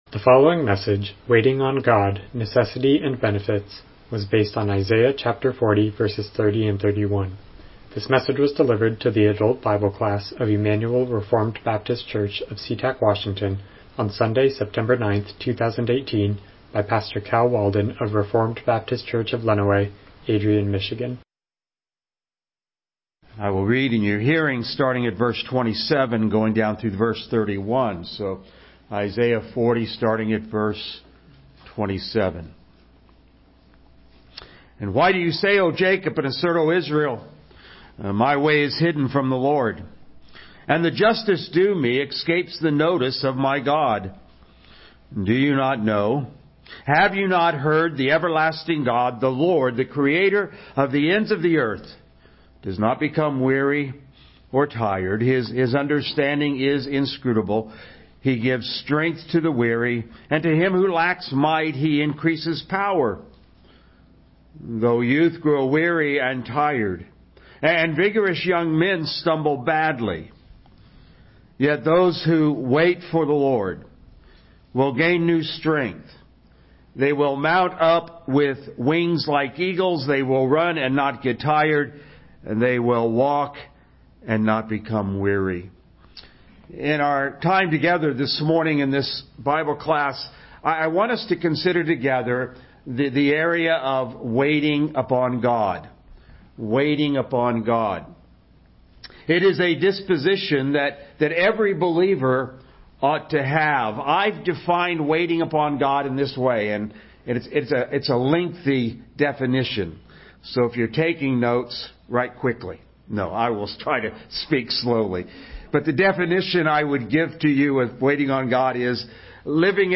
Isaiah 40:30-31 Service Type: Sunday School « Our Posture in the Already Kingdom “And Peter” »